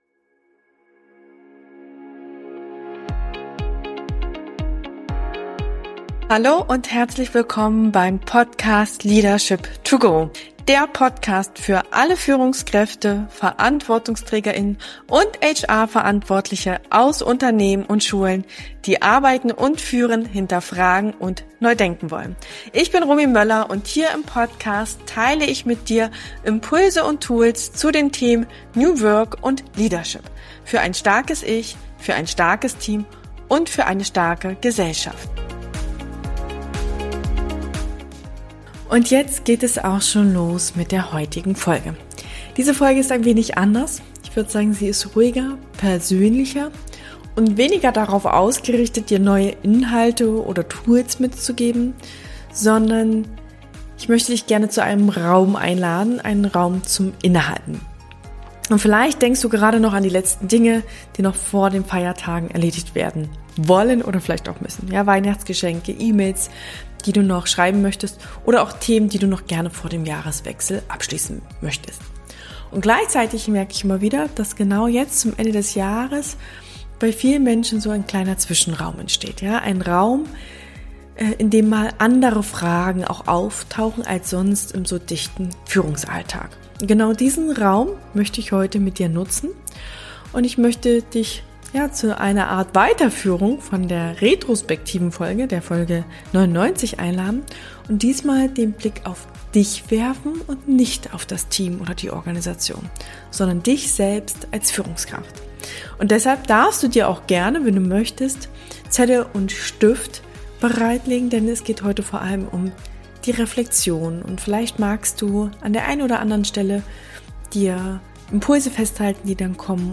In dieser ruhigen, persönlichen Folge lade ich dich ein, innezuhalten und deine eigene Führungsrolle bewusst zu reflektieren.